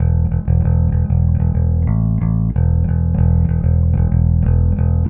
Trem Trance Bass 03b.wav